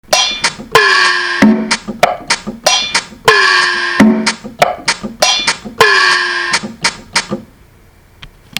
Percussion version.